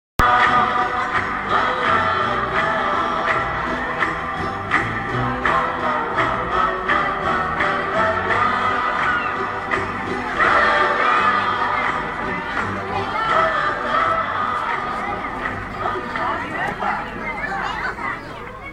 [31/8/2010]听到一首很好听的英文歌，但只录到一段旋律，谁知道歌名吗？